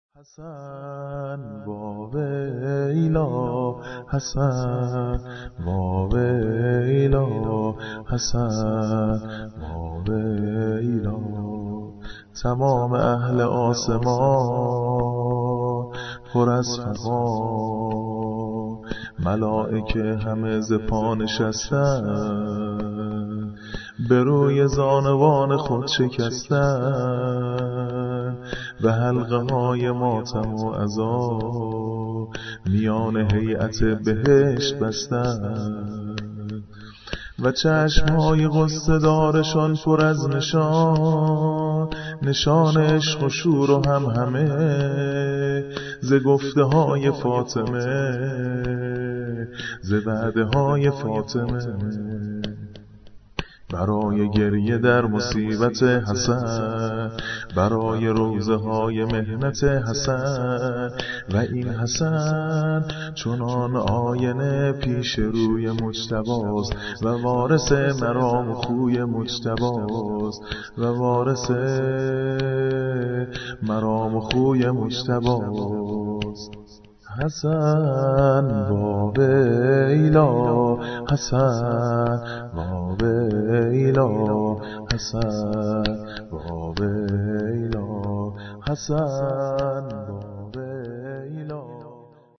نوحه‌ی سینه‌زنی شهادت امام عسکری(ع)